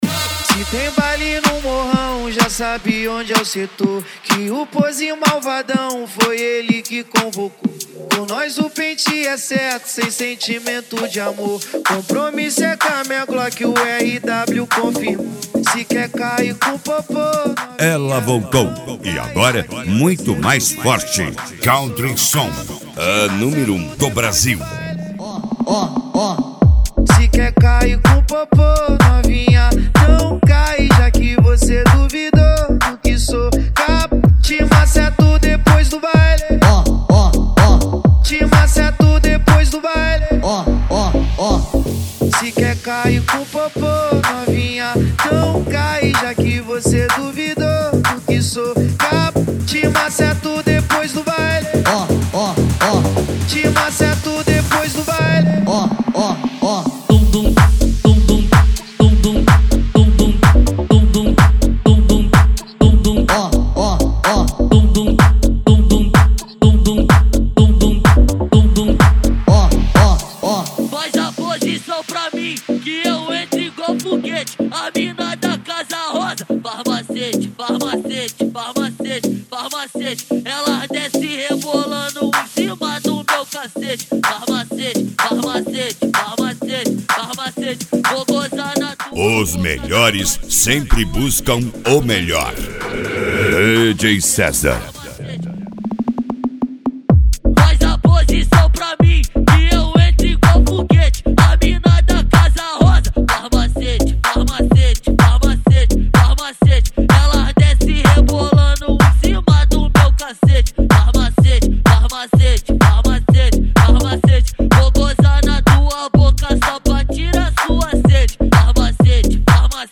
Funk
Funk Nejo
Mega Funk